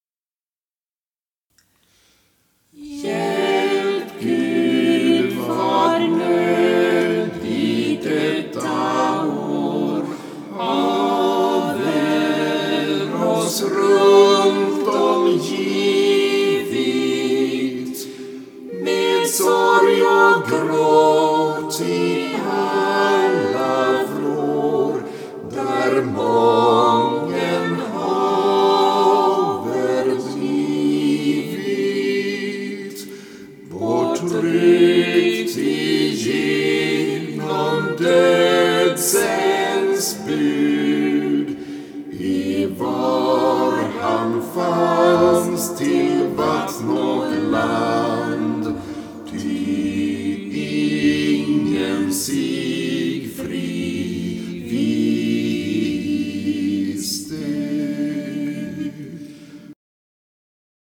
Vokalensemblen KALK